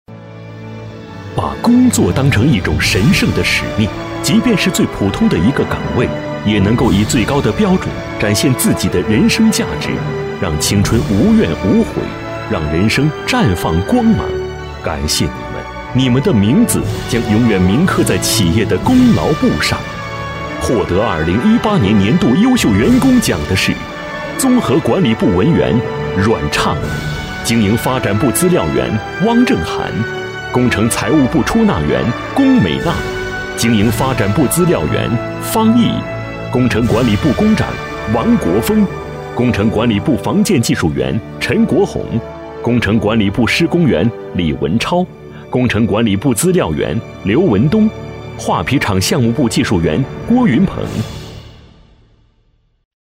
男国24_专题_颁奖_优秀员工奖.mp3